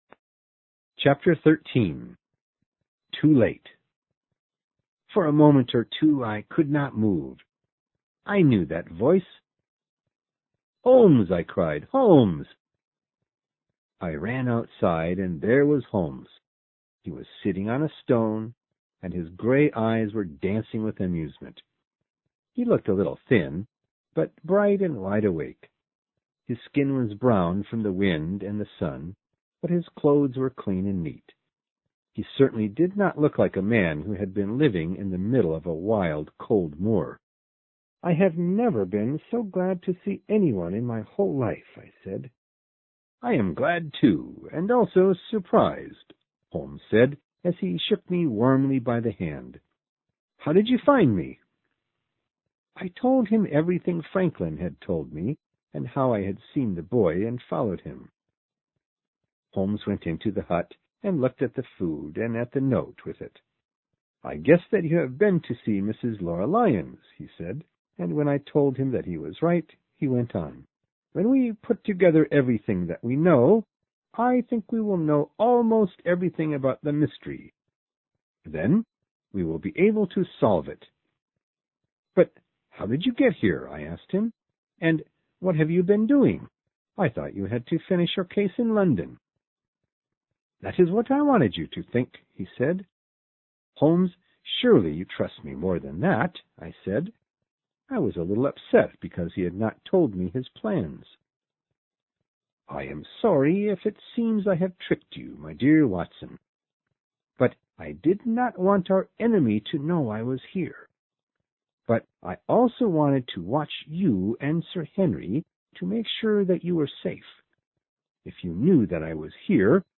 有声名著之巴斯史维尔猎犬 Chapter13 听力文件下载—在线英语听力室